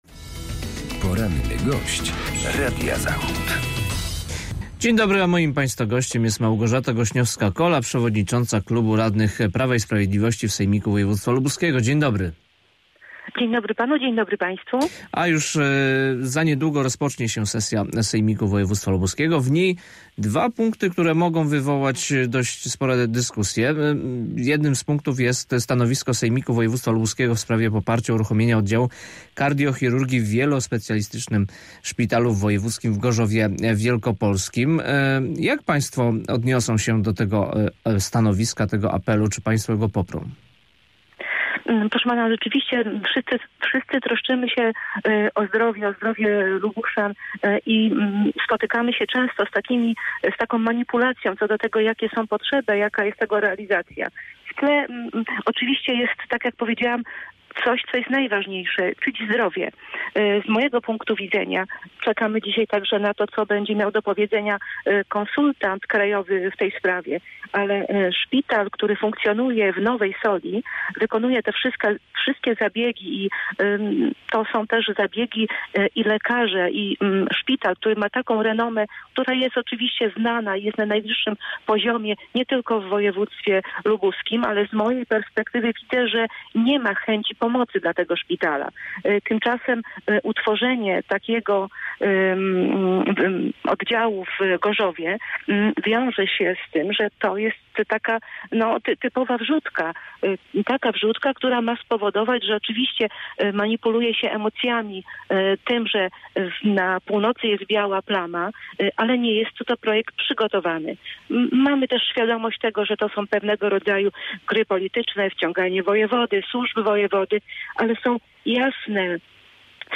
Z radną wojewódzką z klubu PiS rozmawia